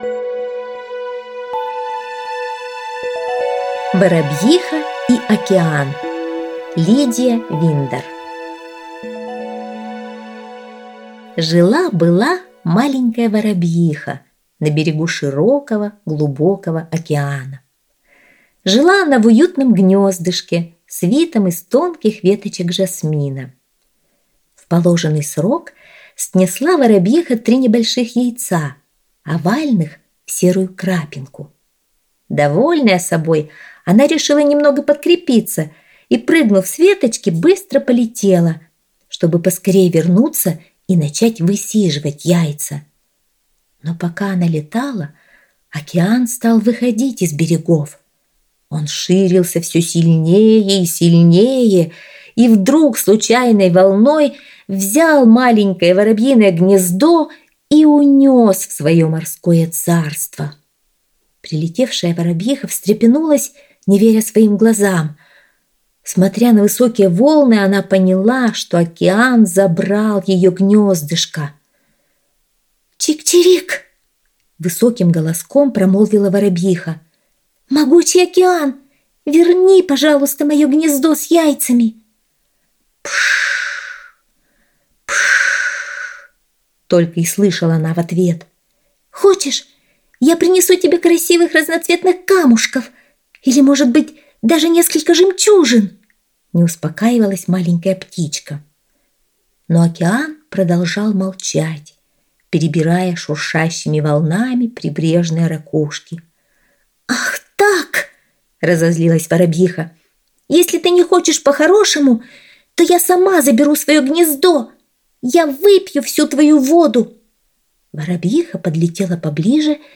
Аудиосказка «Воробьиха и Океан»